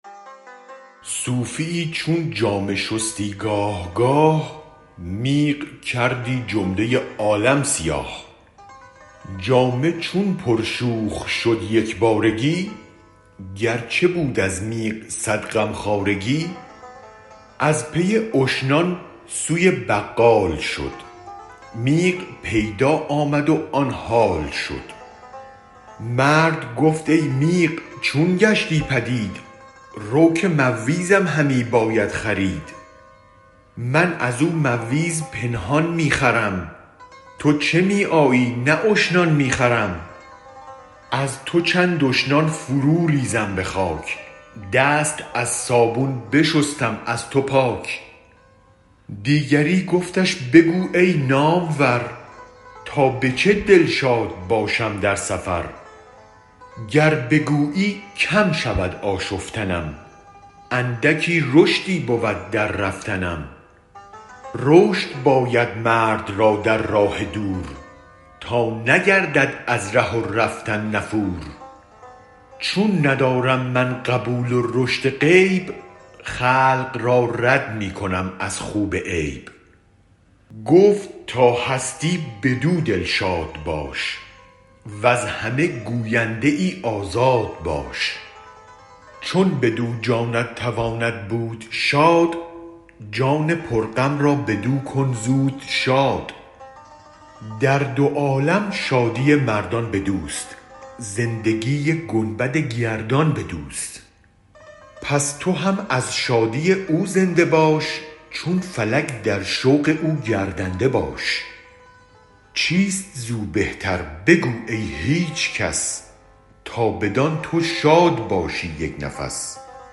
گنجور » نمایش خوانش